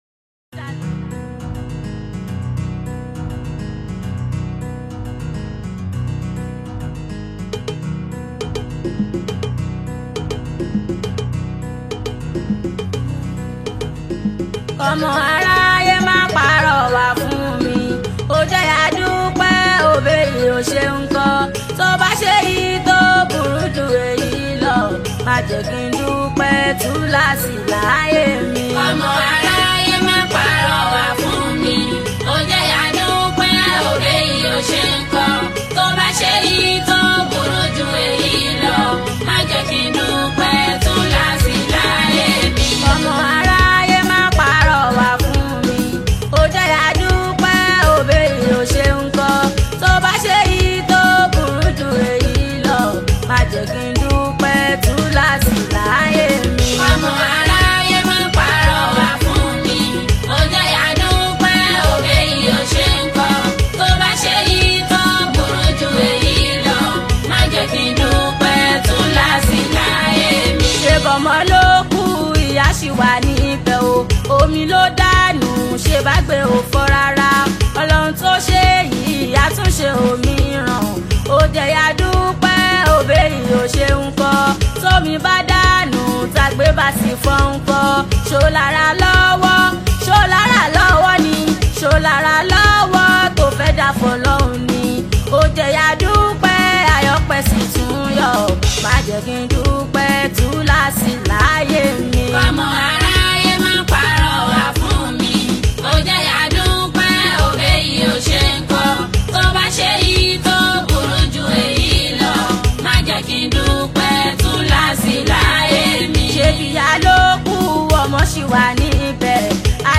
Yoruba Islamic Music 0
Nigerian Yoruba Fuji track
especially if you’re a lover of Yoruba Fuji Sounds